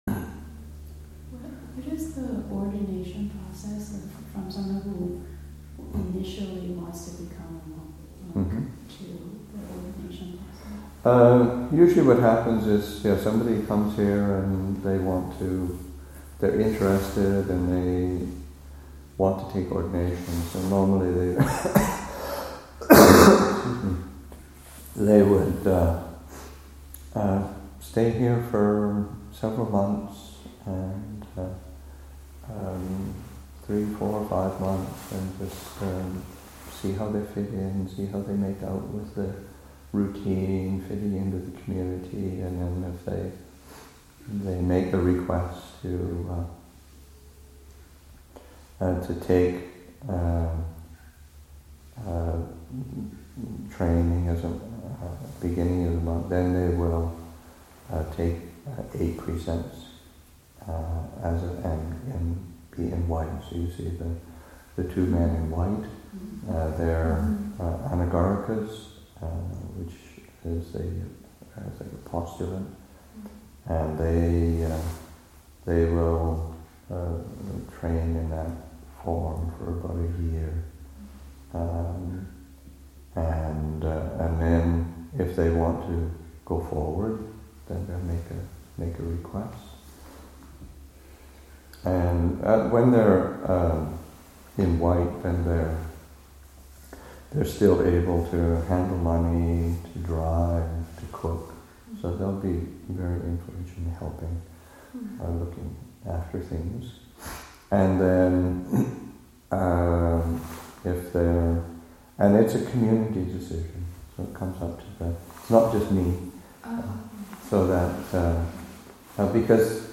Q&A session, Nov. 18, 2014